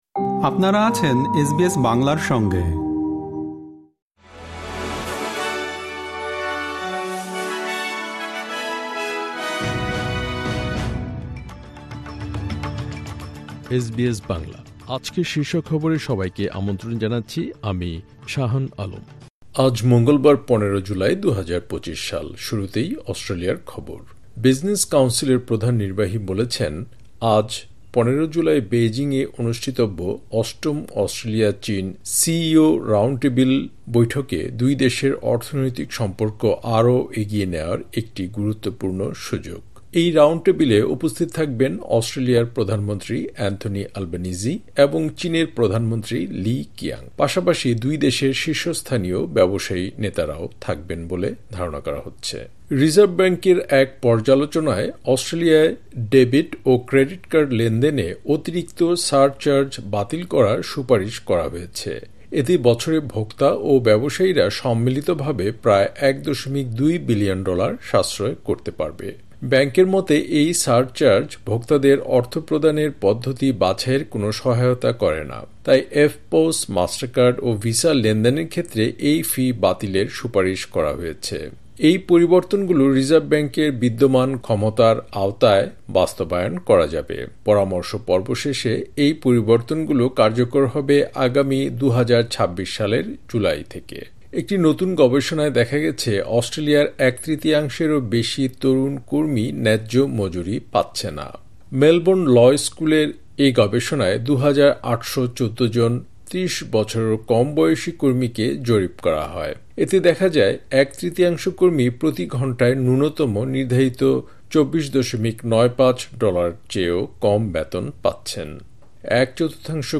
এসবিএস বাংলা শীর্ষ খবর: ১৫ জুলাই, ২০২৫